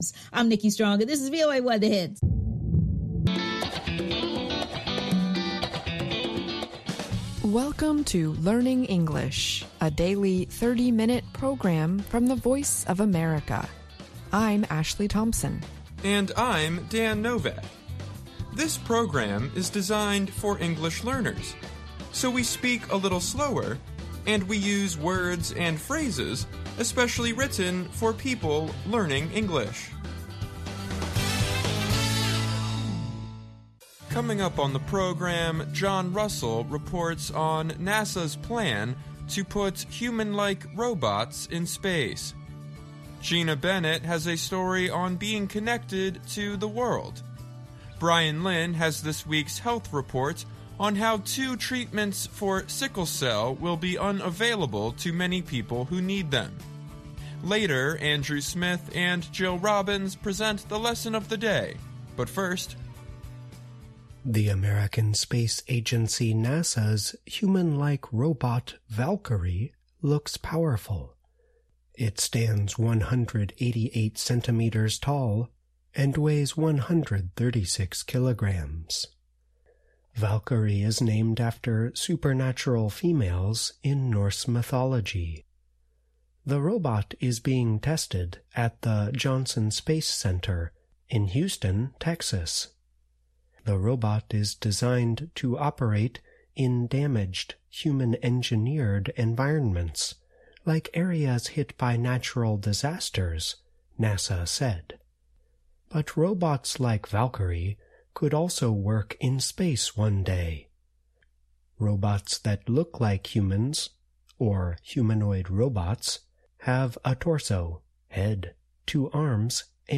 On today’s podcast, NASA aims to use human-like robots for jobs in space; we talk with two teachers who feel connected to the world in different ways; will a costly, new treatment for sickle cell disease be successful in controlling the disease?; and the past perfect tense on Lesson of the Day.